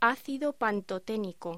Locución: Ácido pantoténico